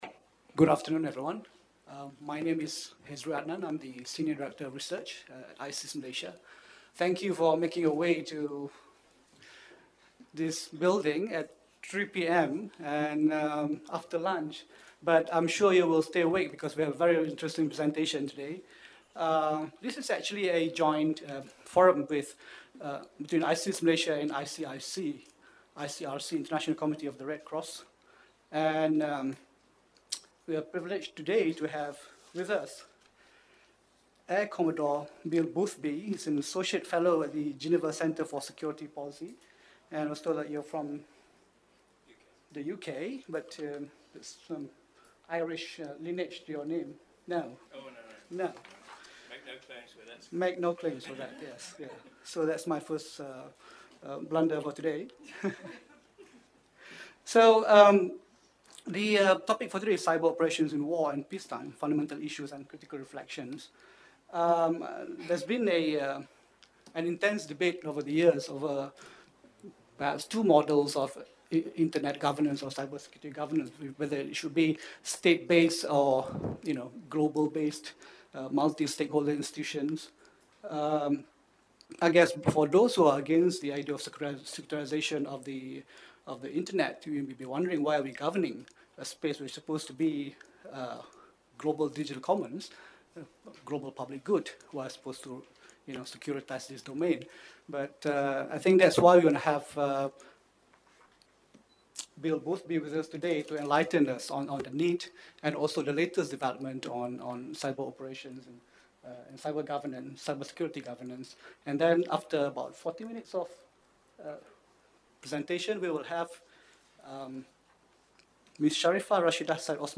Following the presentation is a Malaysian perspective on state operations in cyberspace, articulation the considerations and concerns of smaller powers.
Venue ISIS Malaysia